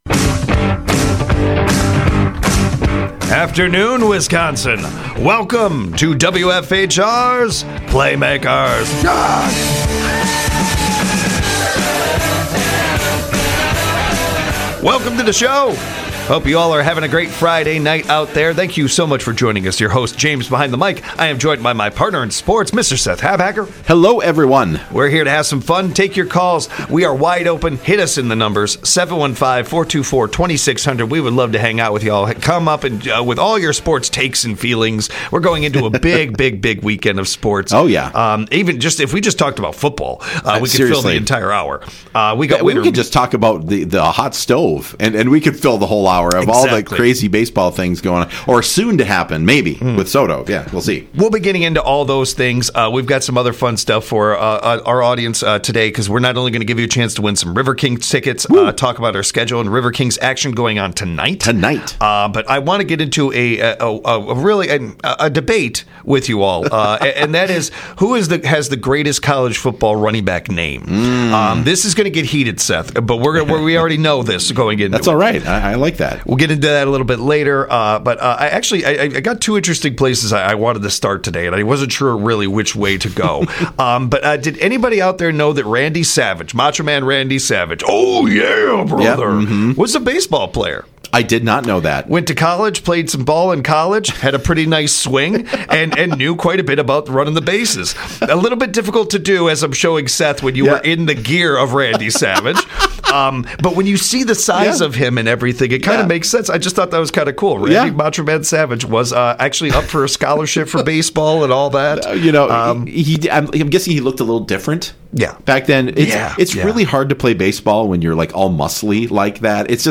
This show takes a deep dive into everything from local to world wide sports. With local sports guests and call-ins from the audience, this show is a highlight every Monday, Wednesday, Friday from 5pm - 6pm on WFHR.